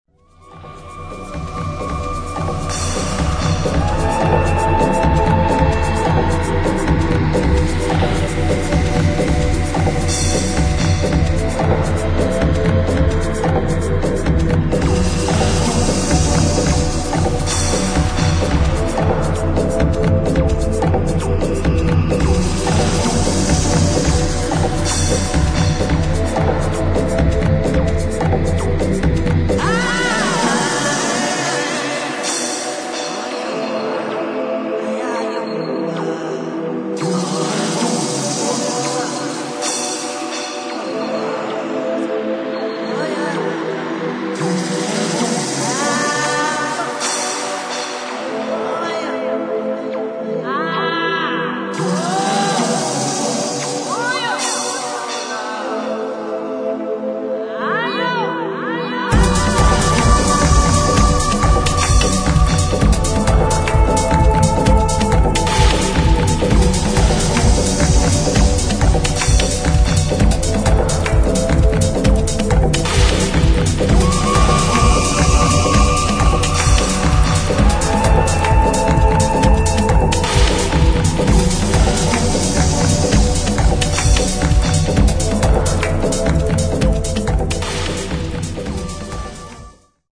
[ TECHNO / ELECTRONIC ]